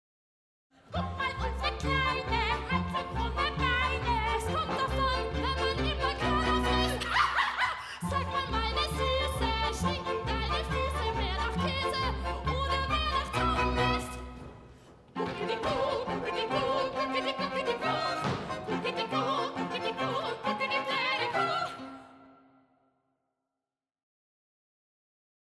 Münchner Erstaufführung am 17. Januar 2015 in der Reithalle
Musiker des Orchesters des Staatstheaters am Gärtnerplatz